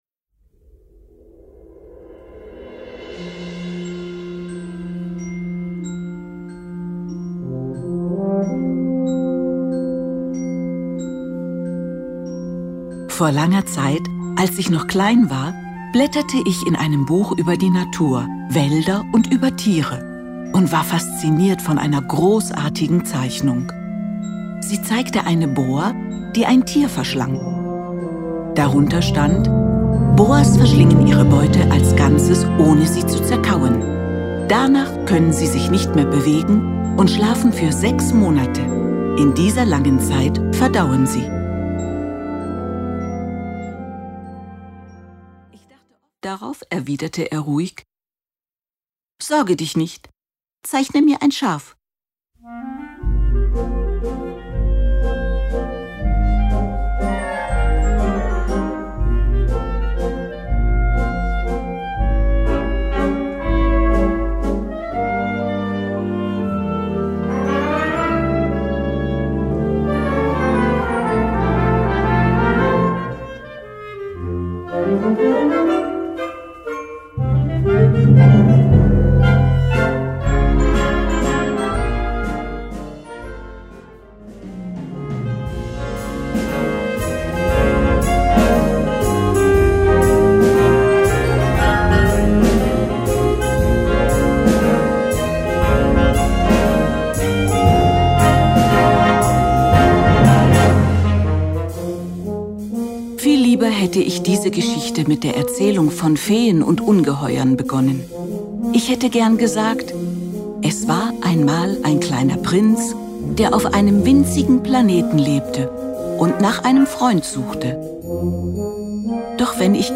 Für Erzähler und Blasorchester